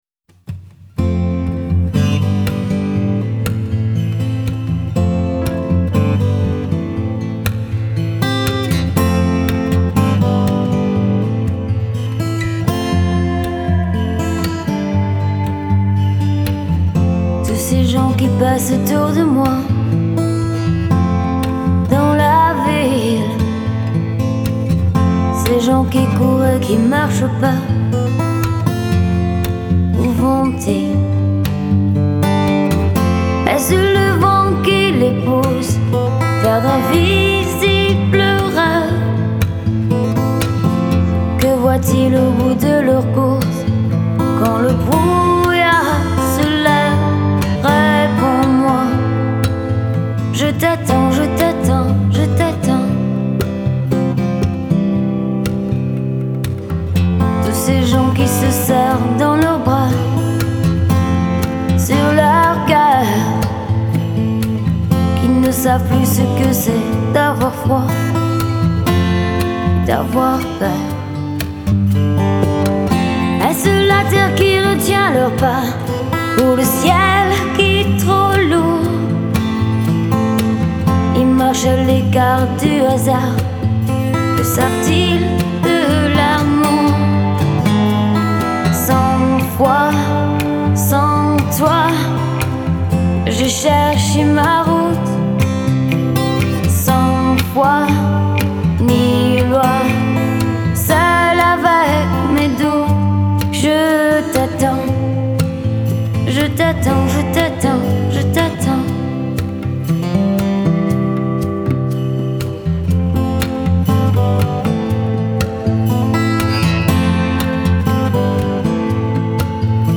Genre : French Music